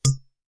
Metalic 2.wav